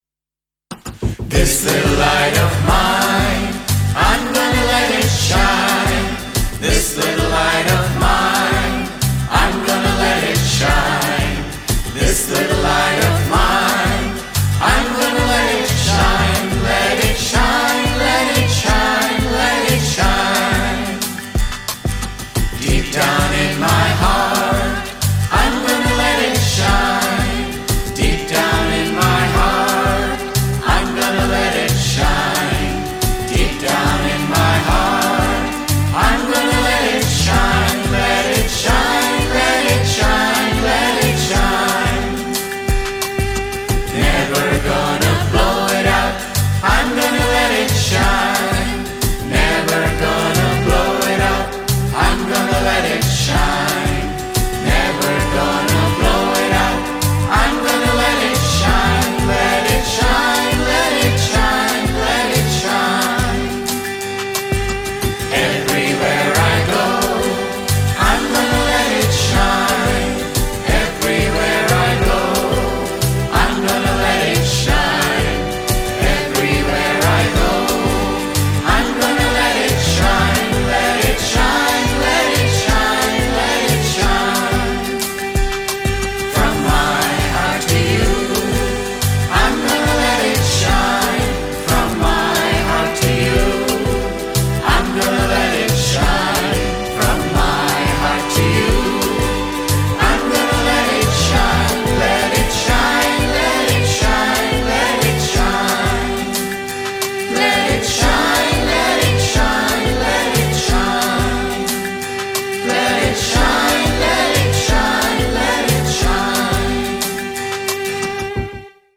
1. Devotional Songs
Major (Shankarabharanam / Bilawal)
8 Beat / Keherwa / Adi
Medium Fast
This song is best sung in unison.